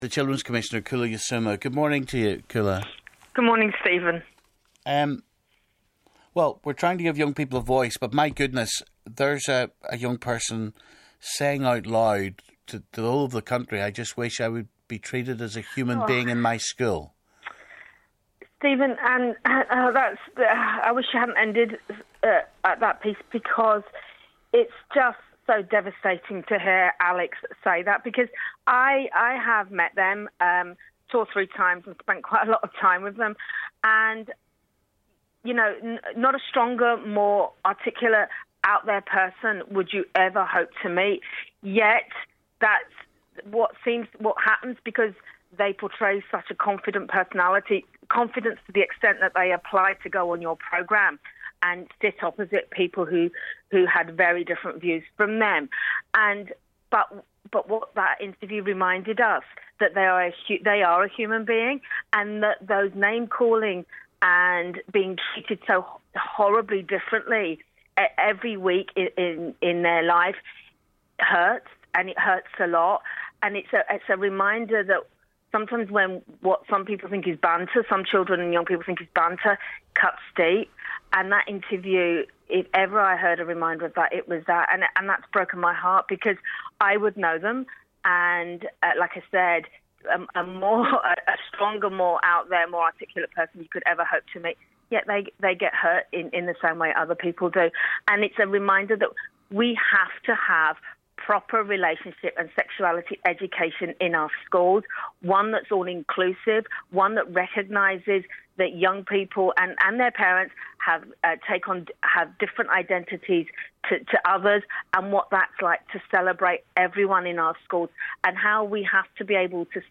NI Children's Commissioner Koulla Yiasouma reacts to last night's Top Table debate and the issue of transphobic bullying.